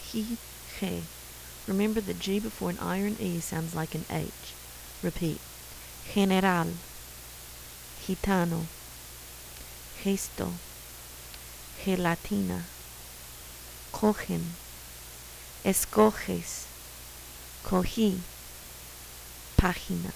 Sounds that are pretty different from English
Sound like h            silent                       sounds like h           sounds like y           sounds like ny